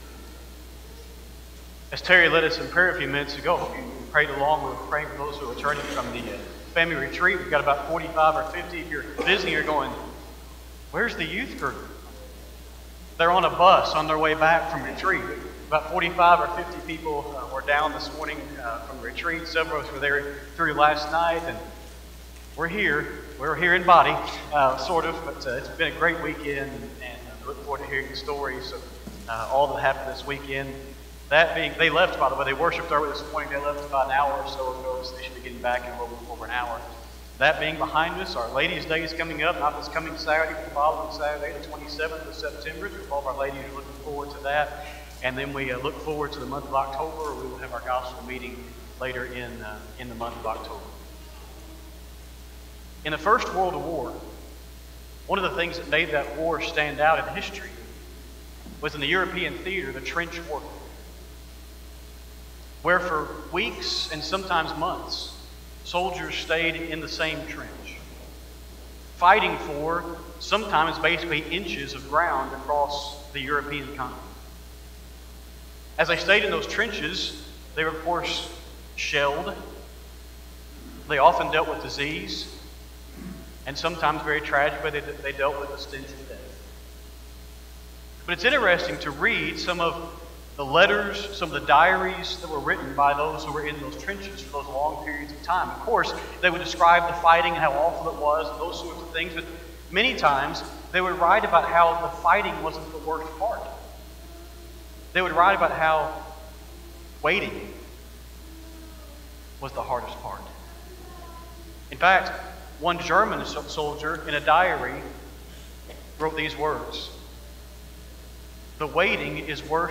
Sunday-AM-Sermon-9-14-25-Audio.mp3